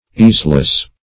easeless - definition of easeless - synonyms, pronunciation, spelling from Free Dictionary Search Result for " easeless" : The Collaborative International Dictionary of English v.0.48: Easeless \Ease"less\, a. Without ease.